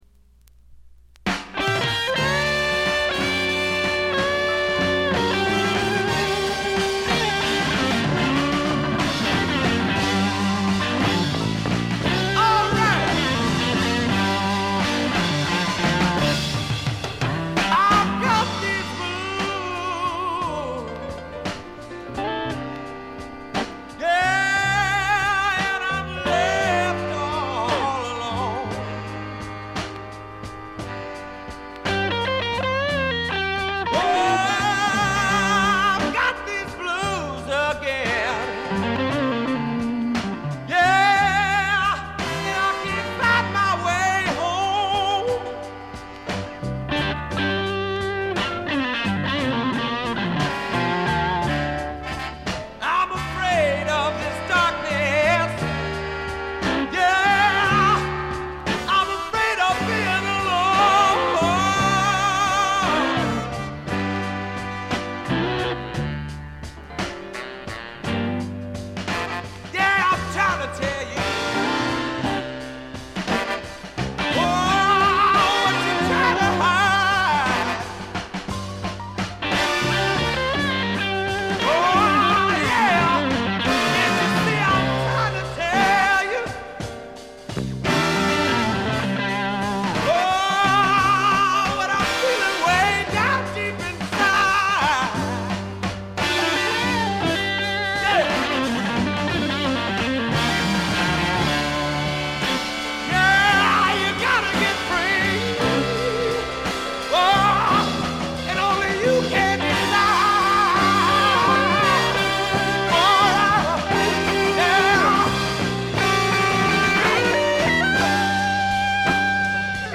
試聴曲は現品からの取り込み音源です。
※中盤でプツ音が出ますが静電気のいたずらか何かでその後再現しませんでした